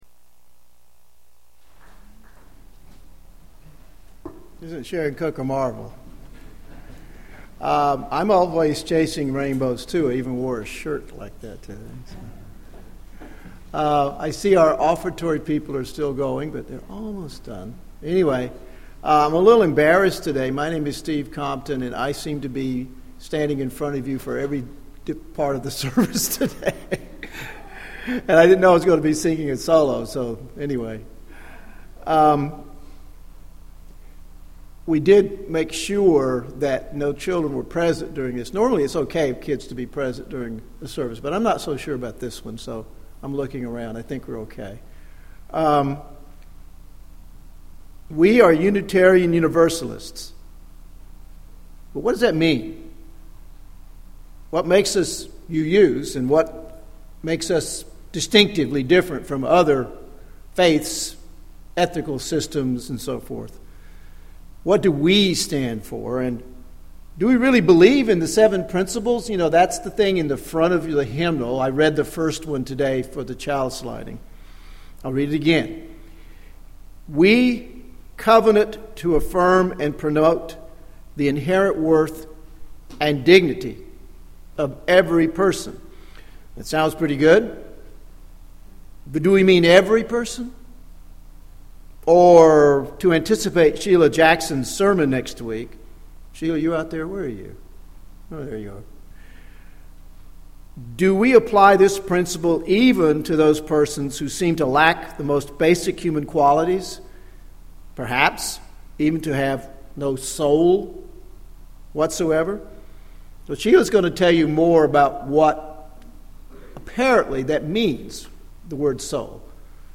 This sermon will feature 10 minutes of talk-back during the service.